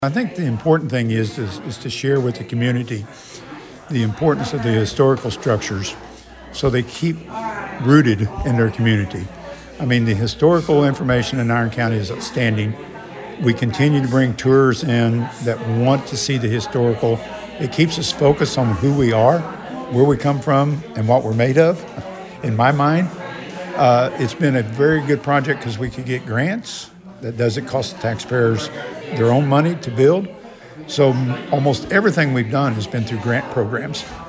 Iron Co., Mo. (KFMO) - The Arcadia Valley Chamber of Commerce held its monthly luncheon Thursday, featuring Iron County Presiding Commissioner Jim Scaggs as the guest speaker.